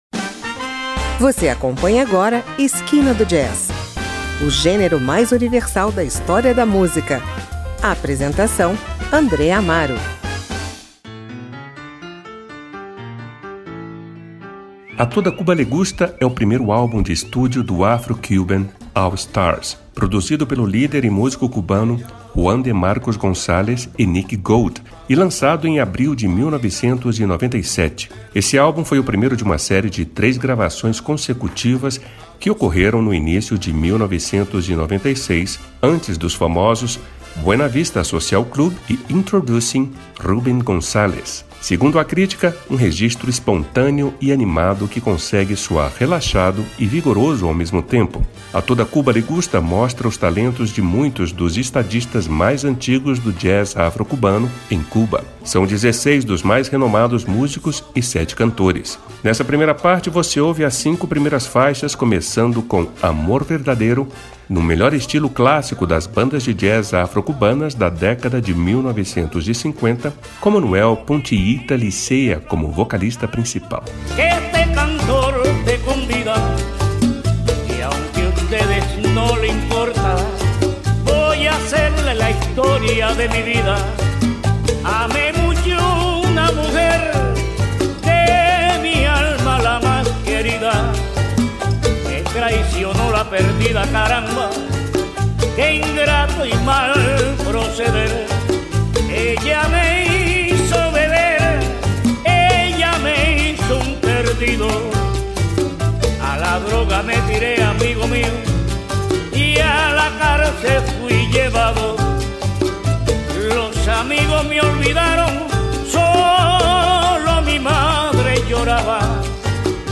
o primeiro gravado em estúdio